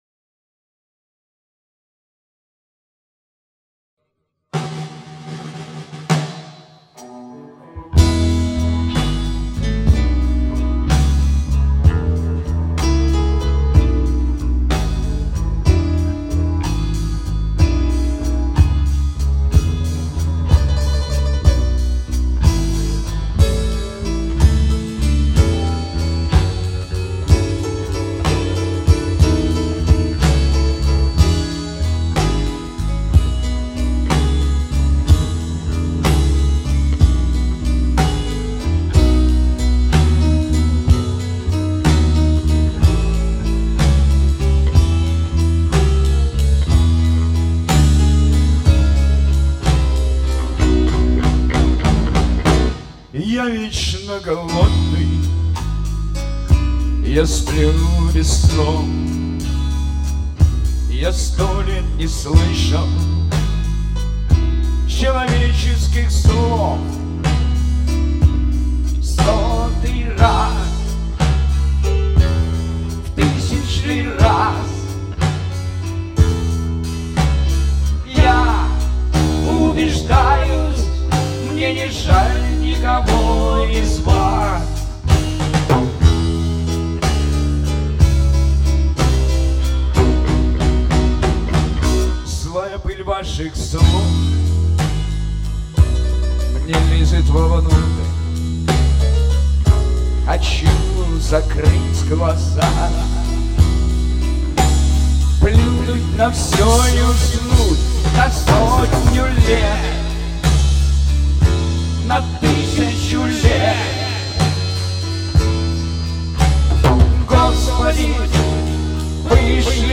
Я как тот Пятачек спешил самым первым принести подарок (шарик)-и не успел ....В общем блюз от недавно состоявшегося в Лисичанске  нашего сейшена -правда еще не совсем сведенного.
По-моему, и так недурственно звучит.